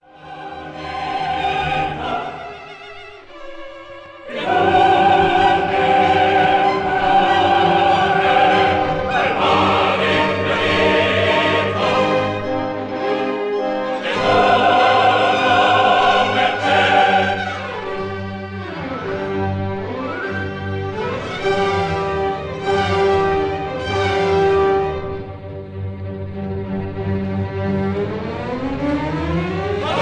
and Orchestra
Recorded in Abbey Road Studio No. 1, London